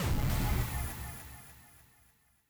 ufo_destroy_001.wav